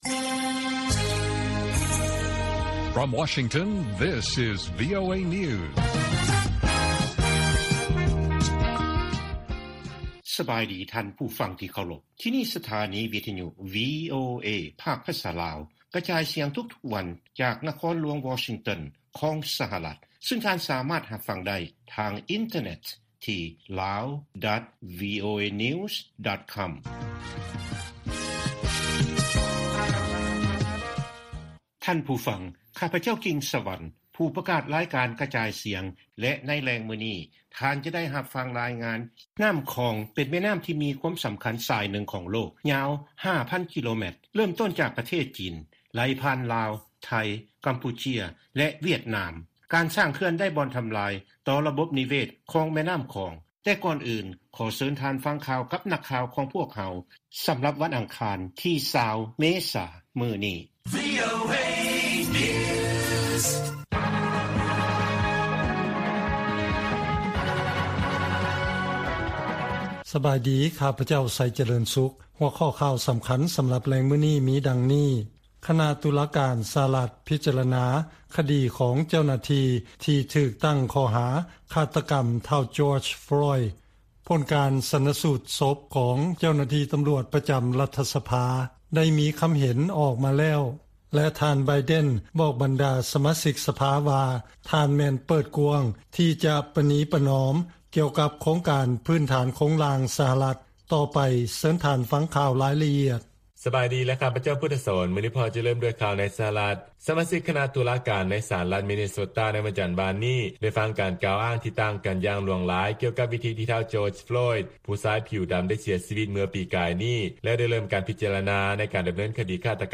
ລາຍການກະຈາຍສຽງຂອງວີໂອເອ ລາວ: ຊາວບ້ານໄທ ກ່າວເຖິງຜົນກະທົບ ຕໍ່ການສ້າງເຂື່ອນ ໃສ່ລຳແມ່ນ້ຳຂອງ
ວີໂອເອພາກພາສາລາວ ກະຈາຍສຽງທຸກໆວັນ.